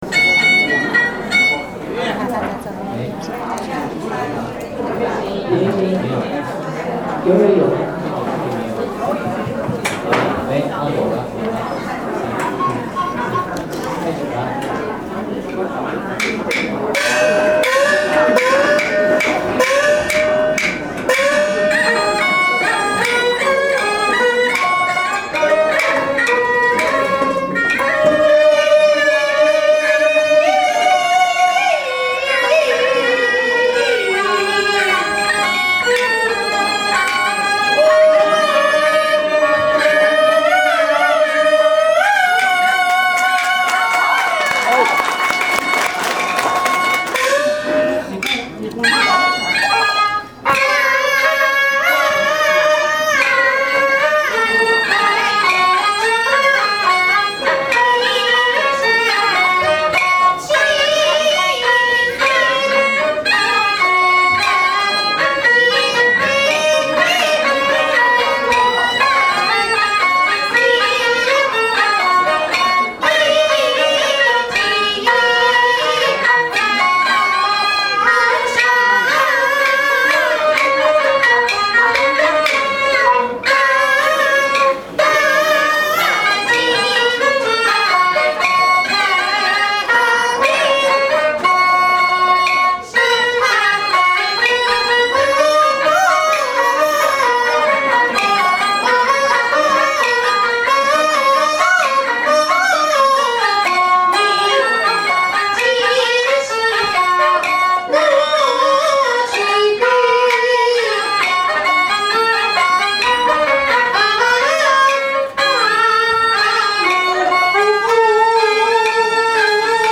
《望江亭》现场选段录音